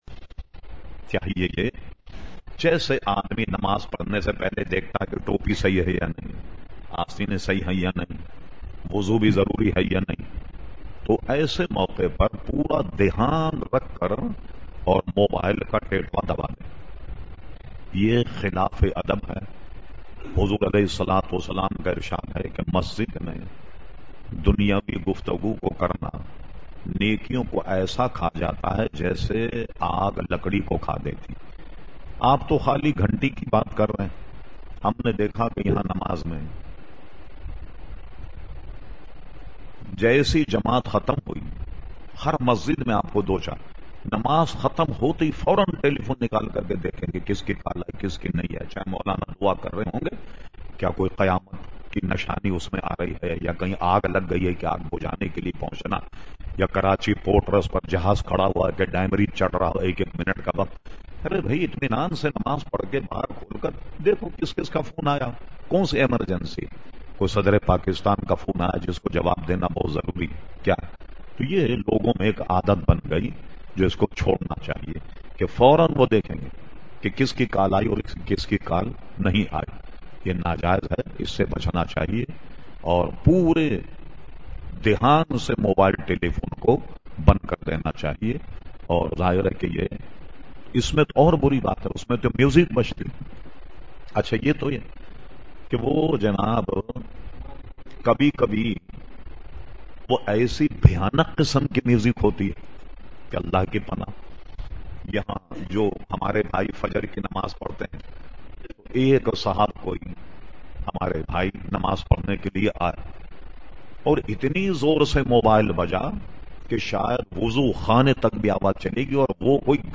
Q/A Program held on Sunday 19 September 2010 at Masjid Habib Karachi.